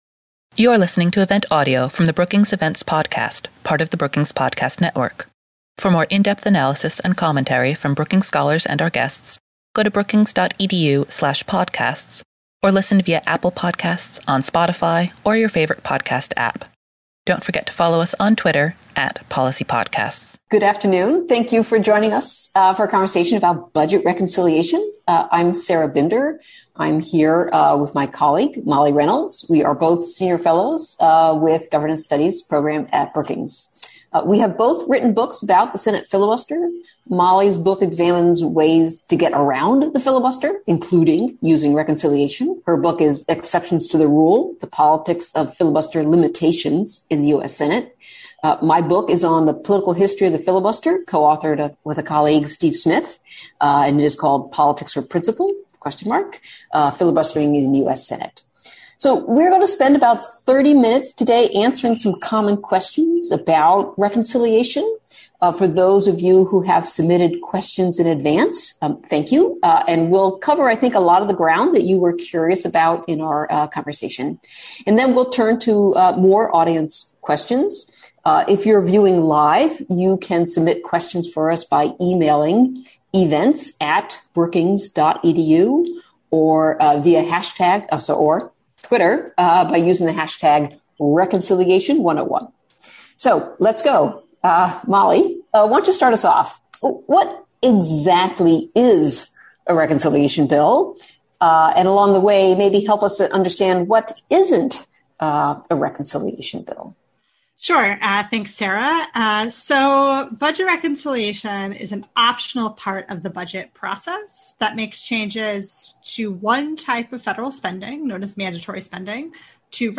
On July 6, Brookings hosted a primer on everything you need to know about reconciliation.